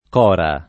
[lat. k 0 ra ]